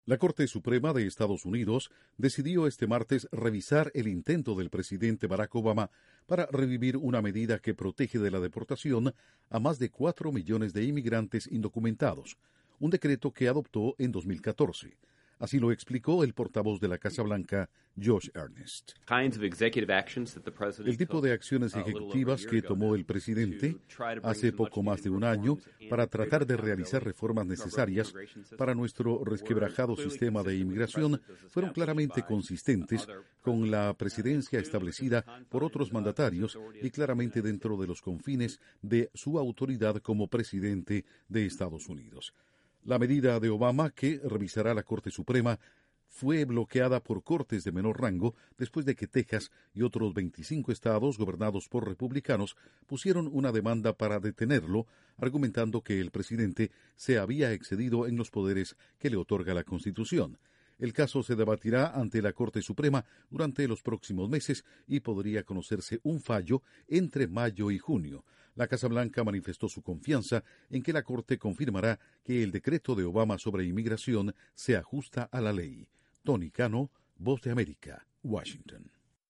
La Casa Blanca se muestra optimista por la decisión que tomará la Corte Suprema sobre sus órdenes ejecutivas de inmigración. Informa desde la Voz de América en Washington